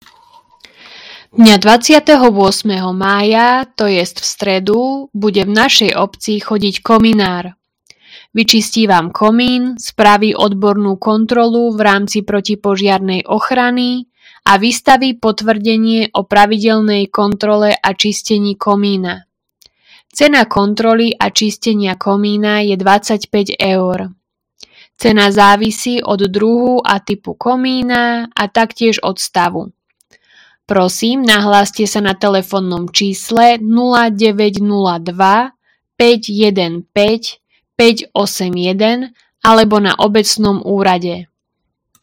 Hlásenie obecného rozhlasu – Kominár – 28.05.2025